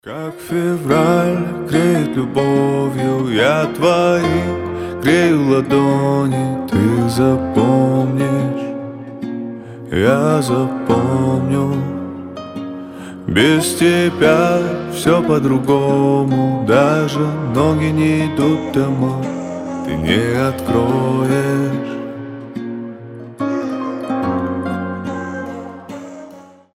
• Качество: 320, Stereo
лирика
грустные
спокойные
медленные